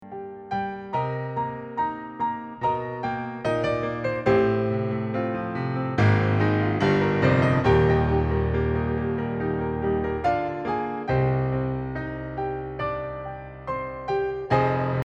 piano versions
reimagined with a more relaxed tone